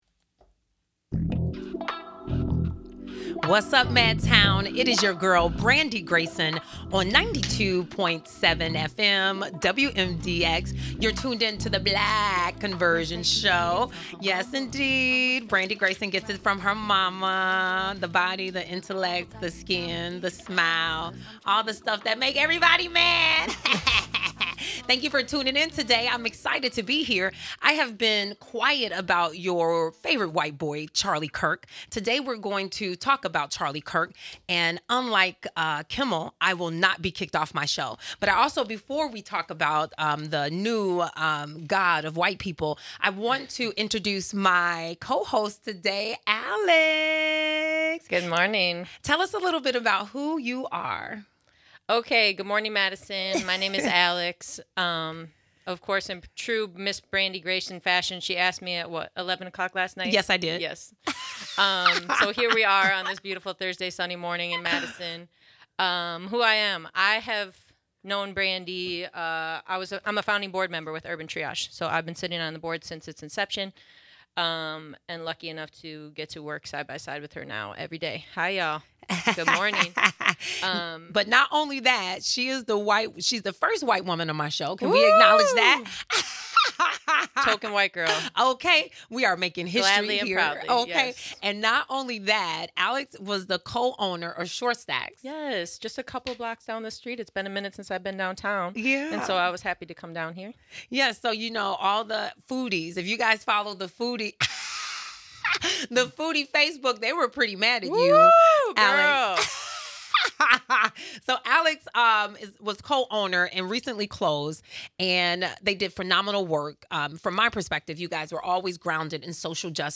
Discussions, guests, and interviews will focus on vulnerable populations--specifically Black families.